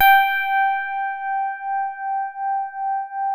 FINE HARD G4.wav